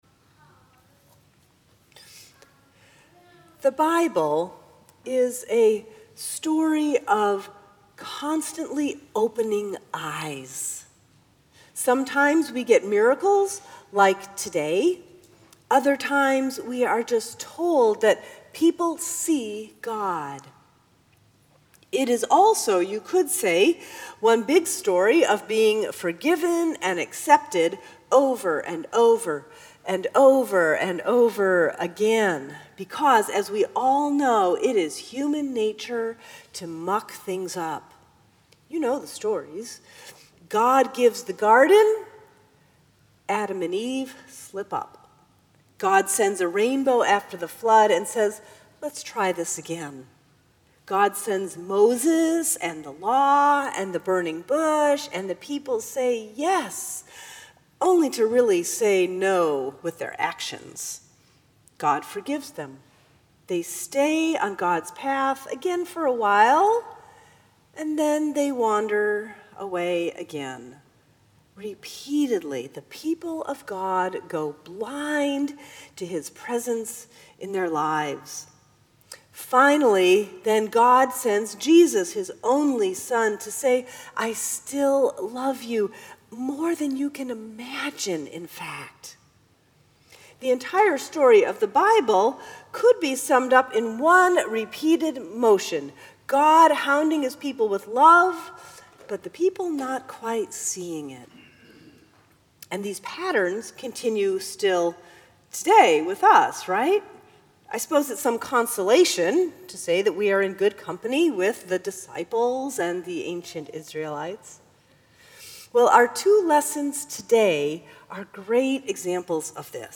March 26, 2017 Fourth Sunday in Lent